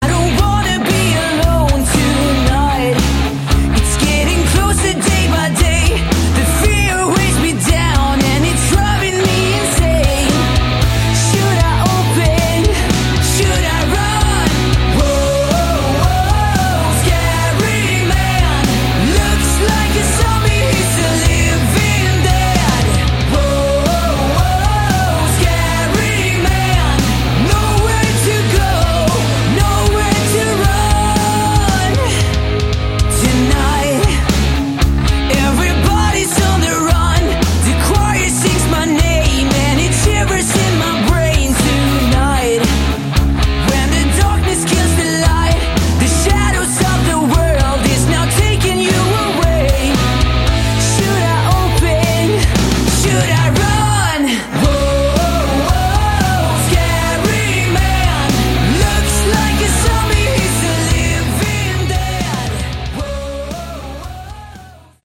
Category: Sleaze Glam / Hard Rock
drums
guitar, Lead vocals
guitar, backing vocals
Bass, backing vocals